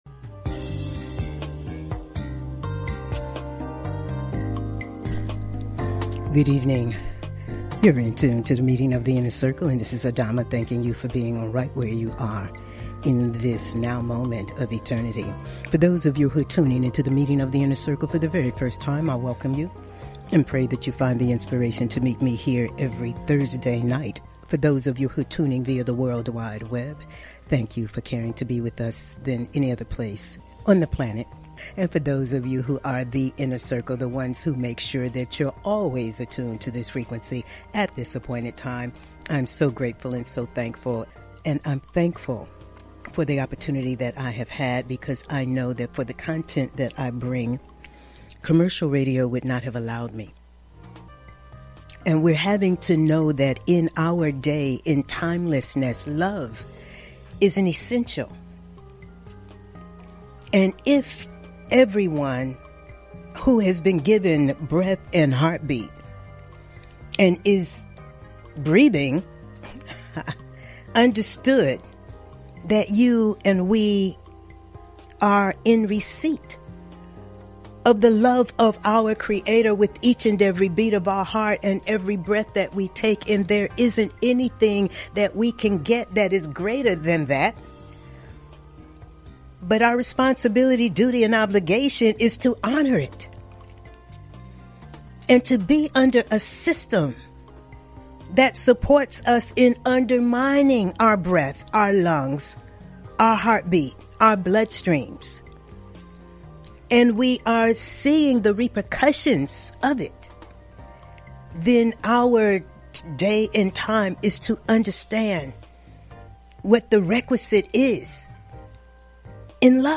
Talk Show Episode
Monologues